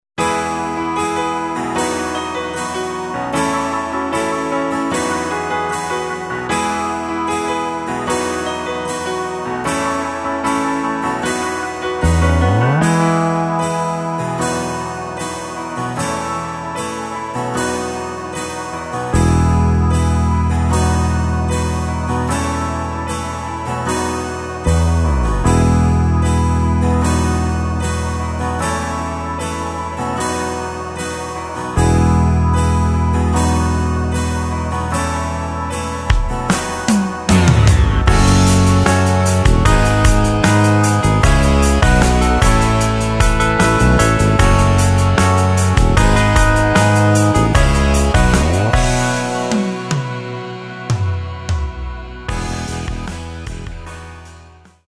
Takt:          2/4
Tempo:         76.00
Tonart:            E
Folk aus dem Jahr 1986!
Playback mp3 Demo